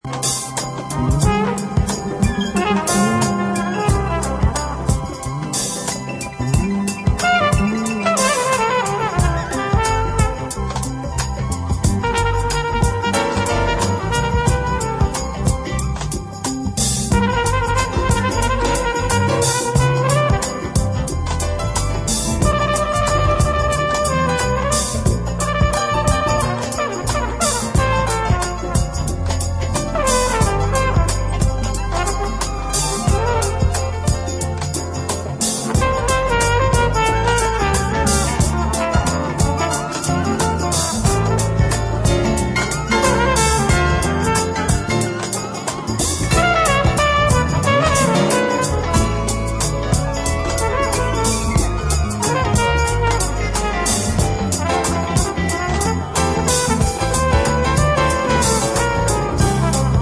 Jazz-funk